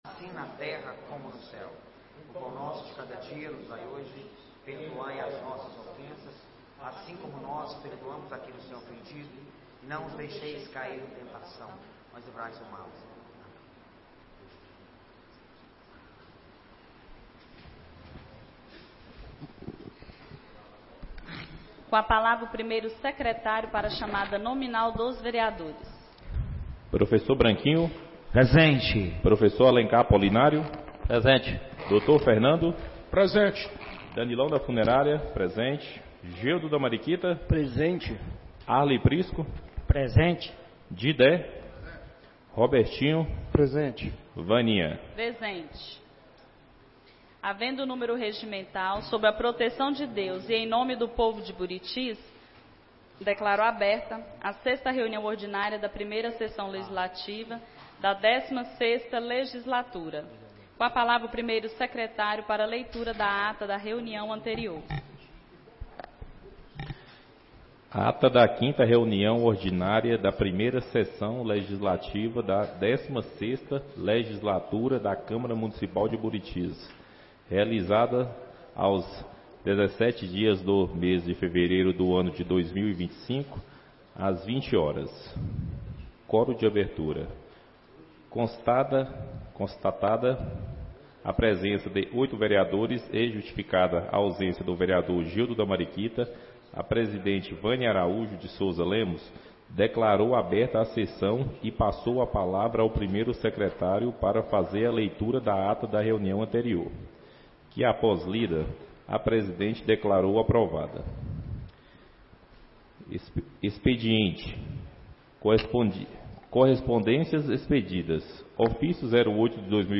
6ª Reunião Ordinária da 1ª Sessão Legislativa da 16ª Legislatura - 24-02-25 — Câmara Municipal de Buritis - MG